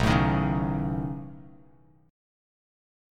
B9 Chord
Listen to B9 strummed